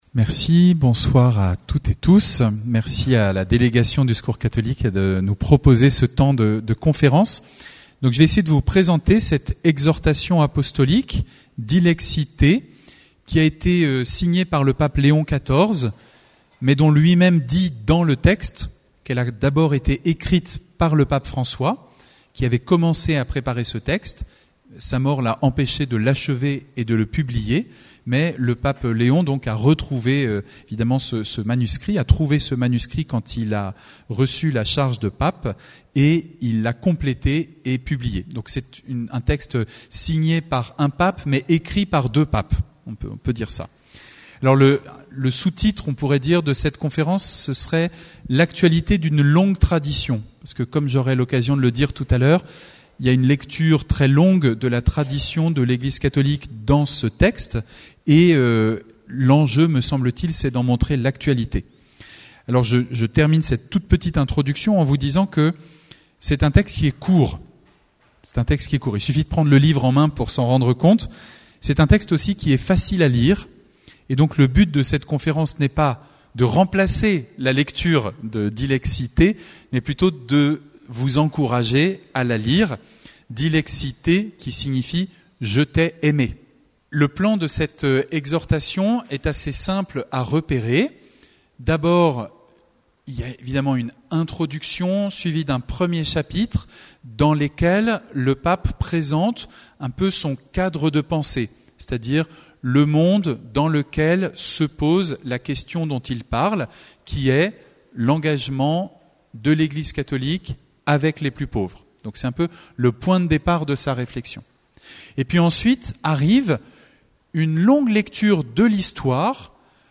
C’était le vendredi 27 février à St Pierre d’Irube.